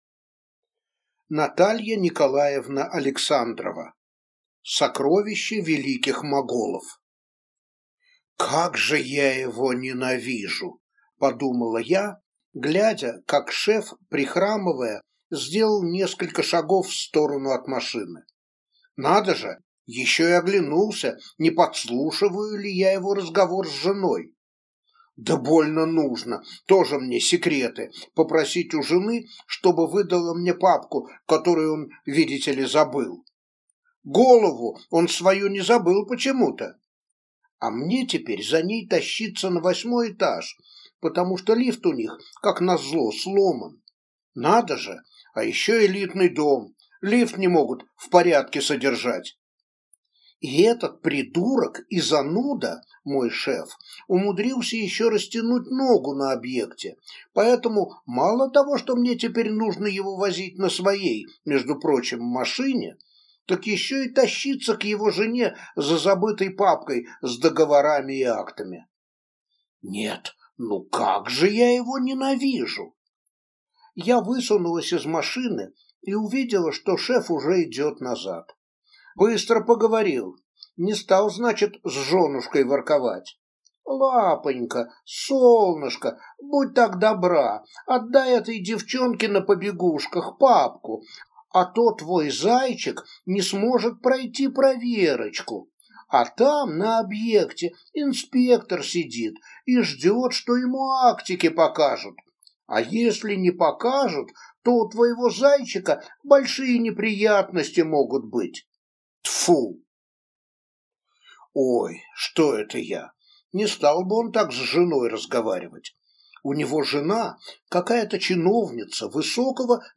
Аудиокнига Сокровище Великих Моголов | Библиотека аудиокниг
Прослушать и бесплатно скачать фрагмент аудиокниги